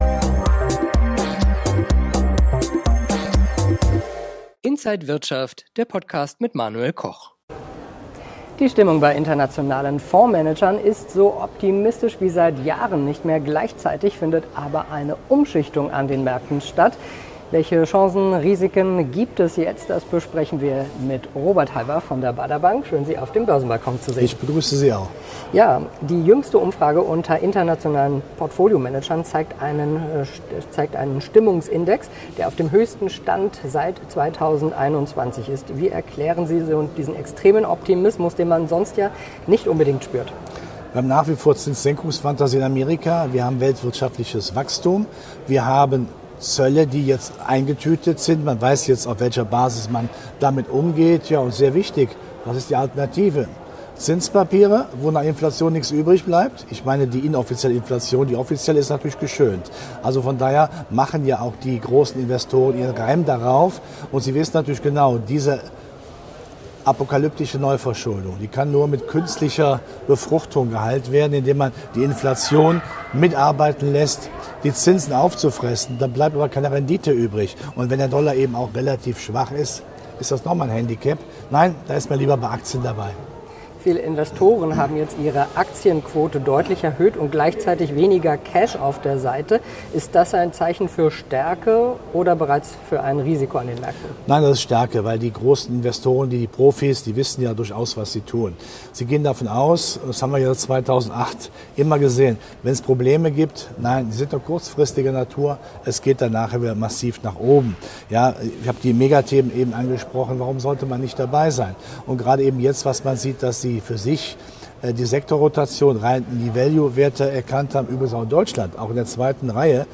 Alle Details im Interview von Inside
an der Frankfurter Börse